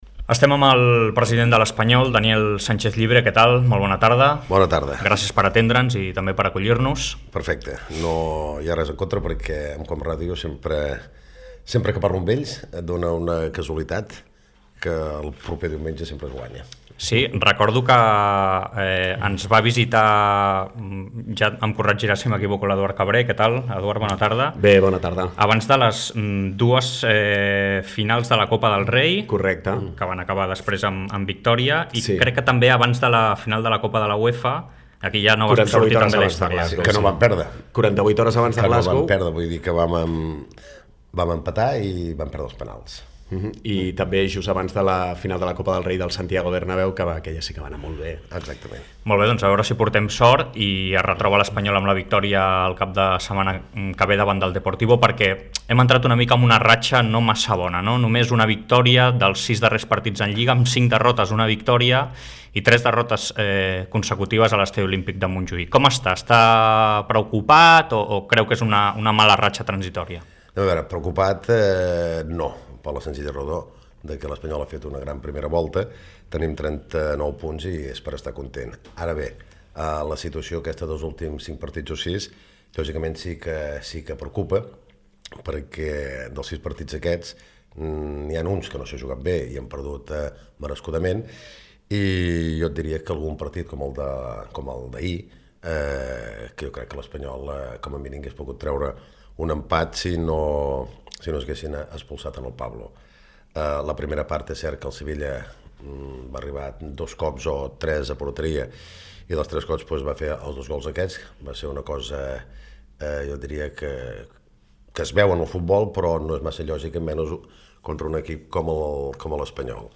Fragment d'una entrevista al president del R.C.D. Espanyol, Daniel Sánchez Llibre.
Esportiu